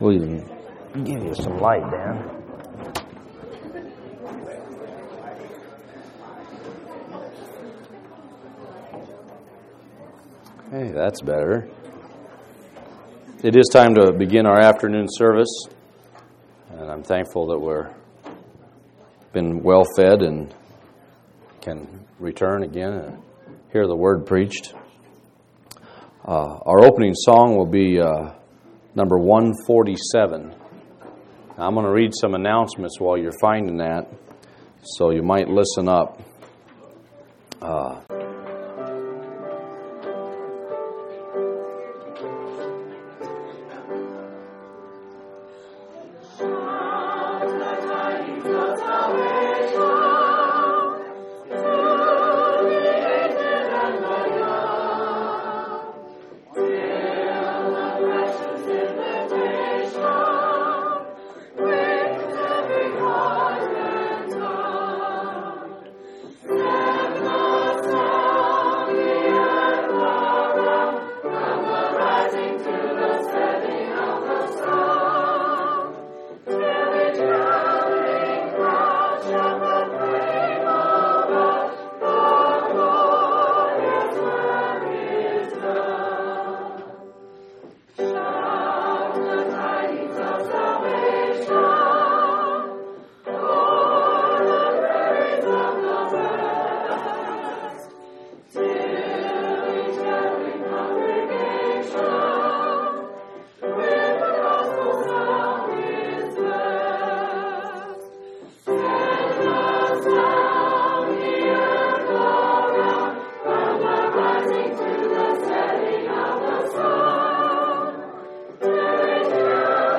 11/28/2003 Location: Phoenix Reunion Event